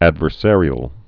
(ădvər-sârē-əl)